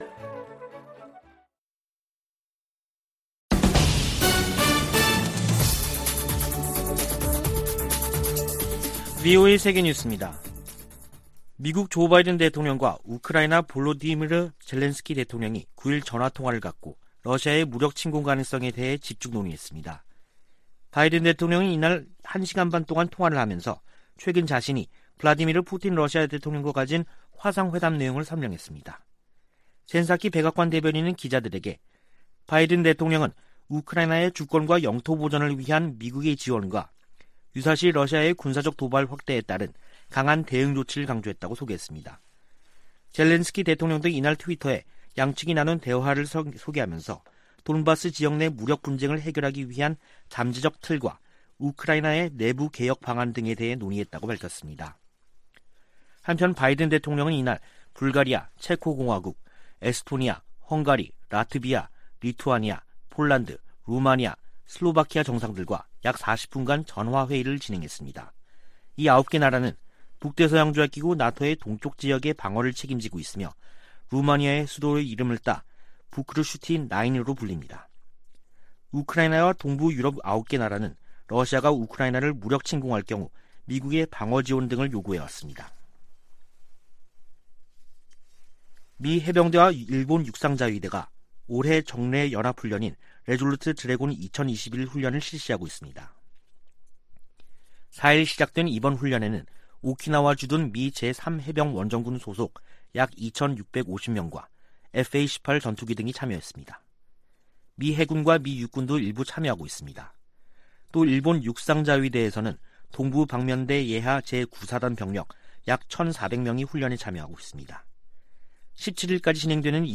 VOA 한국어 간판 뉴스 프로그램 '뉴스 투데이', 2021년 12월 10일 3부 방송입니다. 조 바이든 대통령은 세계 민주주의와 인권이 도전에 직면했다며 지속적인 노력이 필요하다고 강조했습니다. 올해도 유엔 안전보장이사회에서 북한 인권에 대한 공개 회의가 열리지 않는다고 미 국무부가 밝혔습니다. 북한에 각종 디지털 기기들이 보급되면서 외부 정보와 문화에 귀기울이는 젊은이들이 늘고 있습니다.